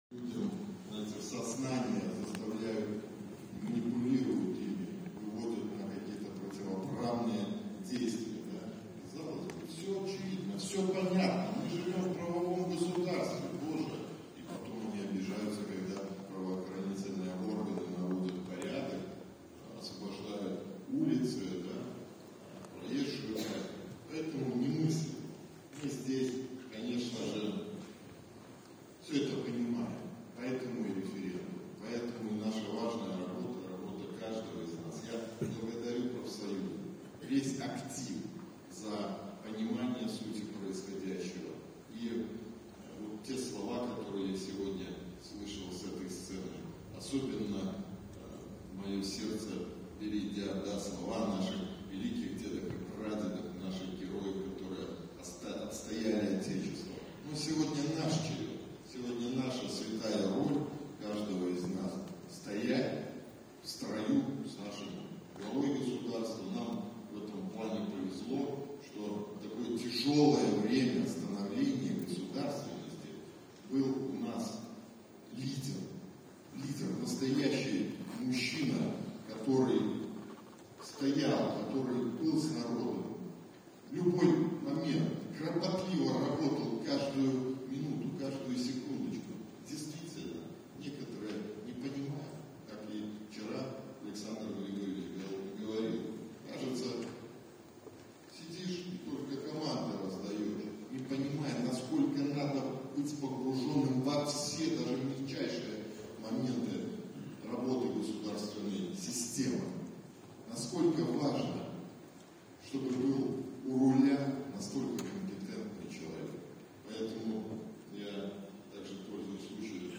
Многократный рекордсмен мира и Европы, член Национальной паралимпийской сборной Беларуси обратился с эмоциональной речью к участникам V пленума ФПБ, который состоялся 29 февраля в Республиканском Дворце культуры профсоюзов.
Аудио запись. фрагмент выступления Талая